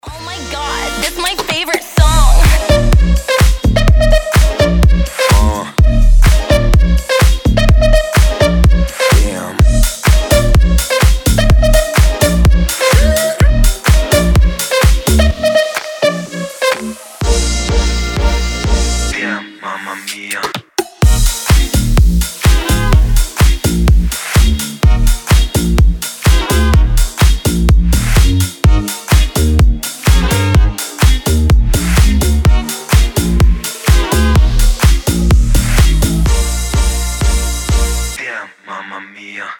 • Качество: 320, Stereo
ритмичные
зажигательные
веселые
EDM
house